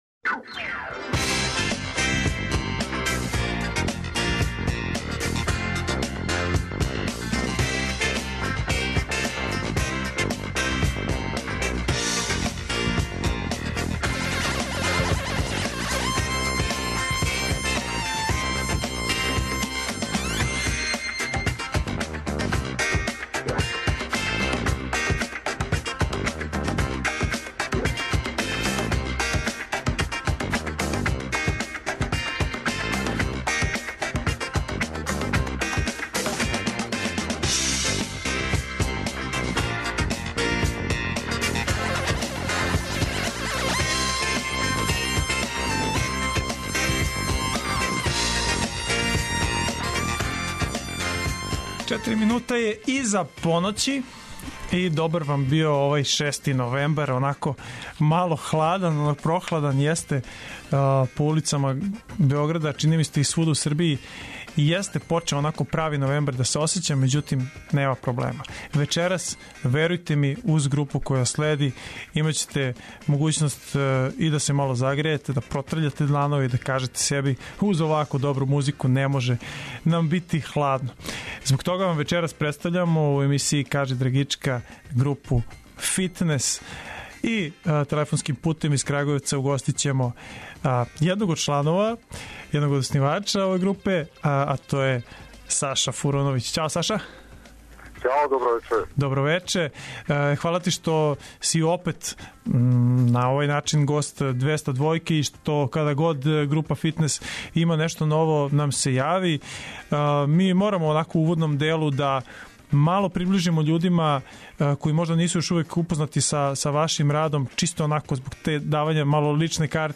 У госте нам долази енергични, инструментални бенд ’’Фитнес’’ из Крагујевца.